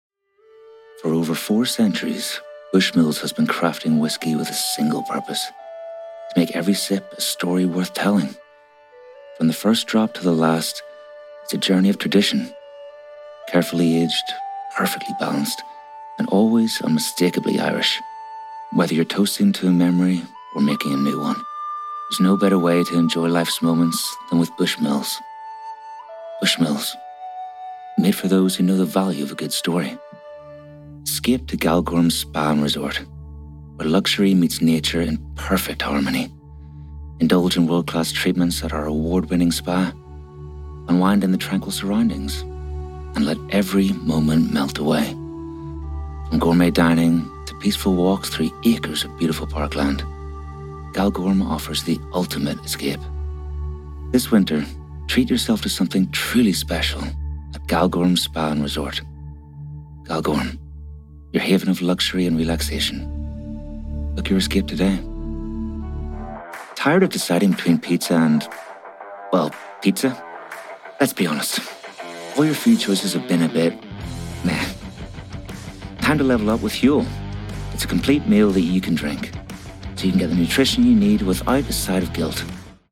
20s-40s. Male. Northern Irish.
Commercials